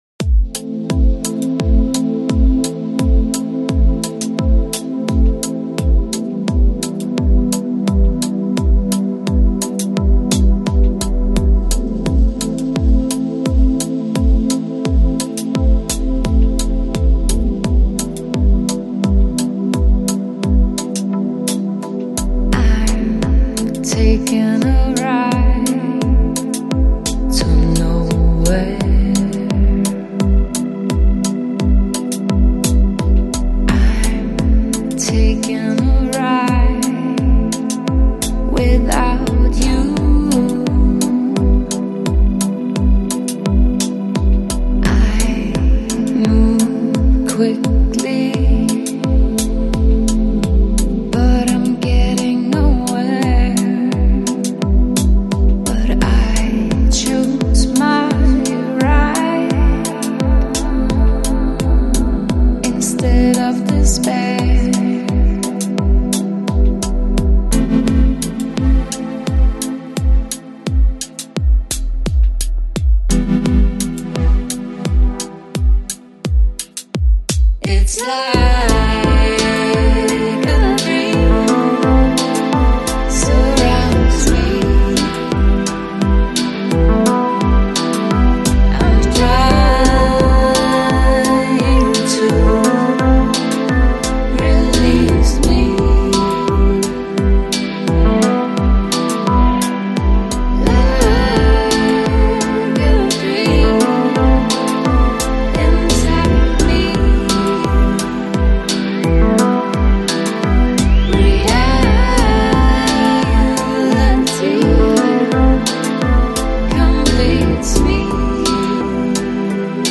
Жанр: Electronic, Lounge, Chill Out, Downtempo, Balearic